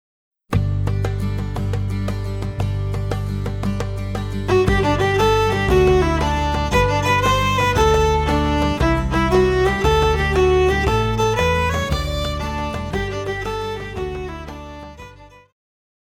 Pop
Viola
Band
Instrumental
World Music,Electronic Music
Only backing